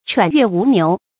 喘月吴牛 chuǎn yuè wú niú 成语解释 比喻因受某事物之苦而畏惧其类似者 成语出处 五代 谭用之《寄王侍御》诗：“ 喘月吴牛 知夜至，嘶风胡马识秋来。” 成语简拼 cywn 成语注音 ㄔㄨㄢˇ ㄩㄝˋ ㄨˊ ㄋㄧㄨˊ 感情色彩 中性成语 成语用法 作主语、宾语；用于书面语 成语结构 偏正式成语 产生年代 古代成语 近 义 词 吴牛喘月 成语例子 吠尧桀犬浑多事，喘月吴牛苦问天。